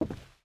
Footsteps_Wood_Walk_01.wav